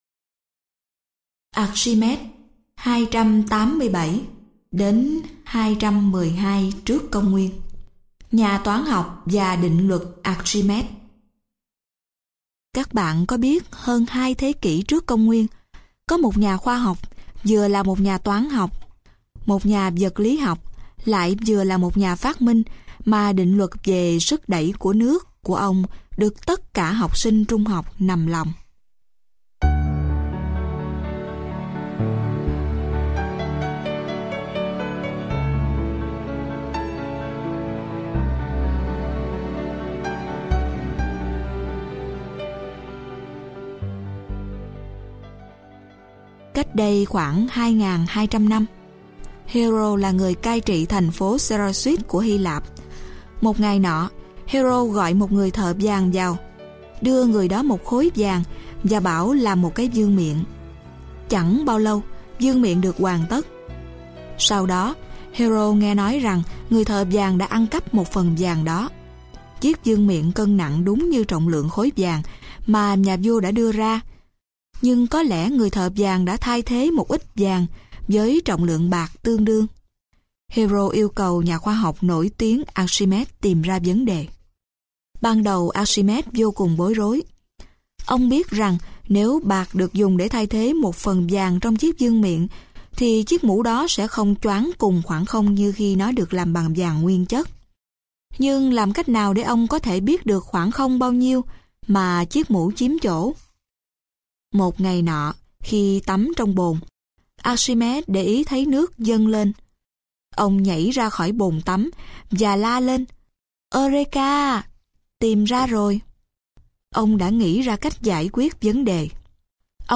Sách nói Các Nhà Khoa Học Và Những Phát Minh - Sách Nói Online Hay